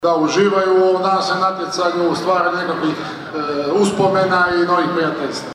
Pozdravi i svečano paljenje plamena